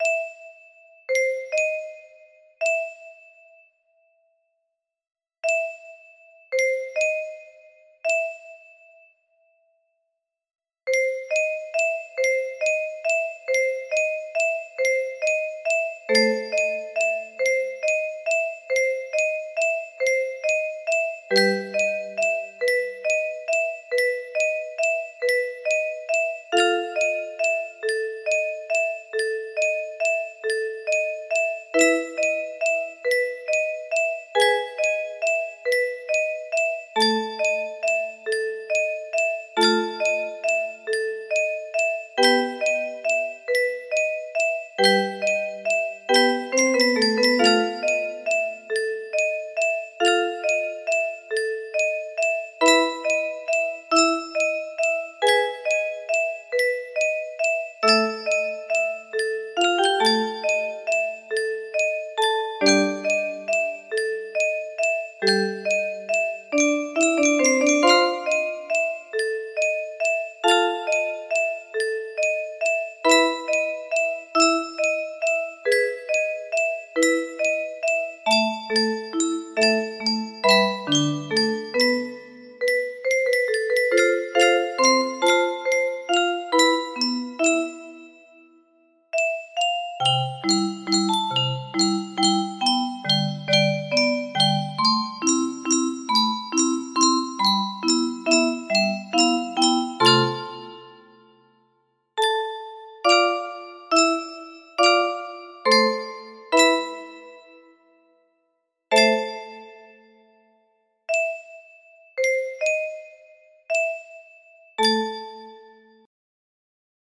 Best I could do with the 30-note